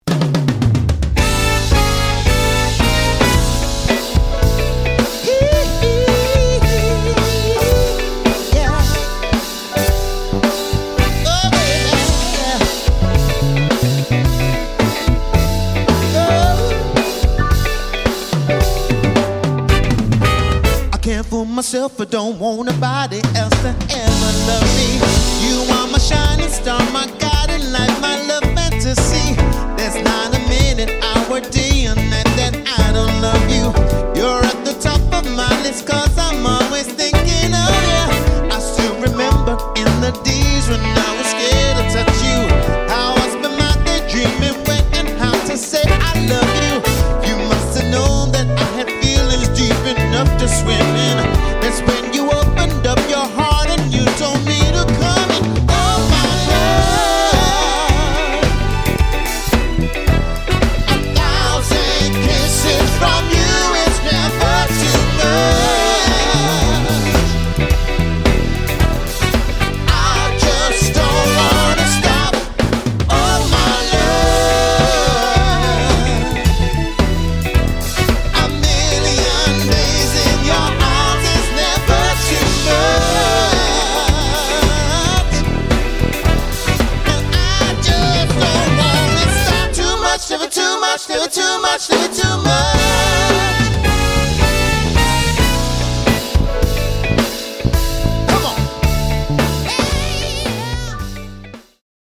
Reviving funk, soul and disco for the 21st century.